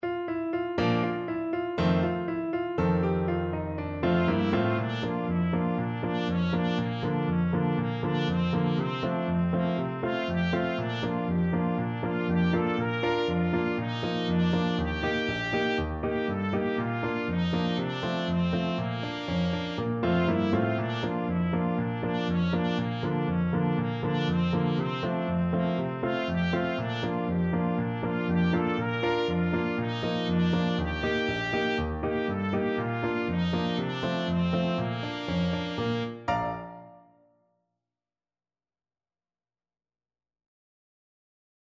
Trumpet version
Traditional Music of unknown author.
4/4 (View more 4/4 Music)
Molto allegro
Bb4-Bb5
Classical (View more Classical Trumpet Music)